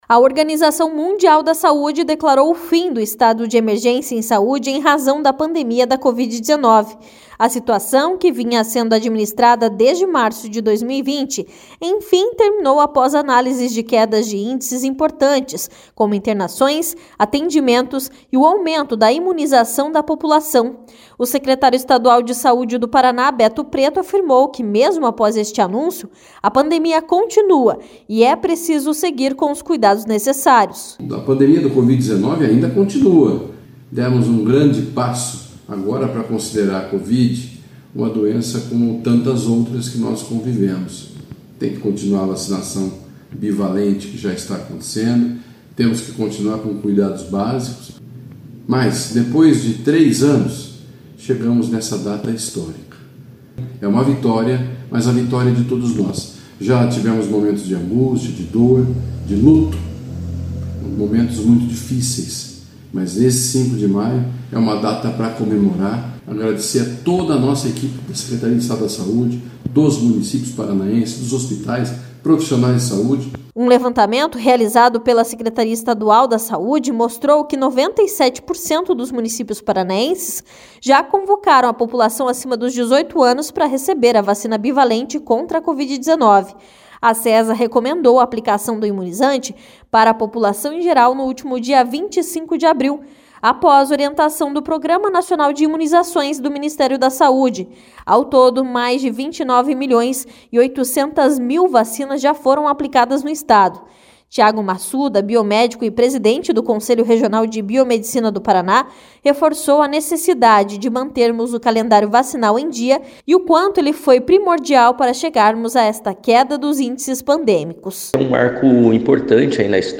O secretário Estadual de Saúde do Paraná, Beto Preto, afirmou que mesmo após este anúncio a pandemia continua e é preciso seguir com os cuidados necessários.